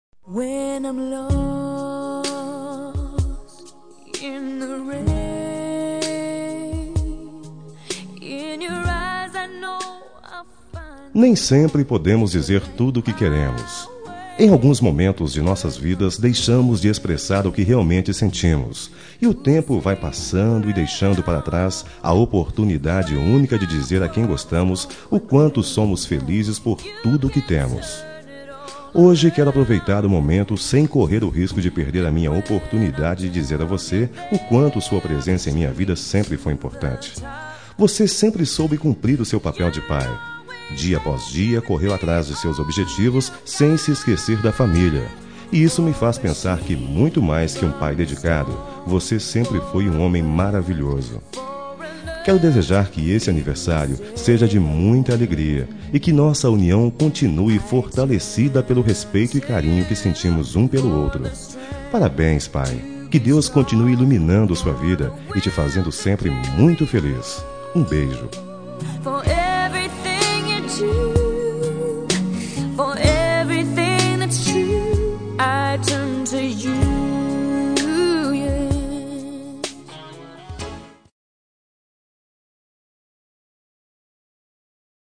Telemensagem de Aniversário de Pai – Voz Masculina – Cód: 1491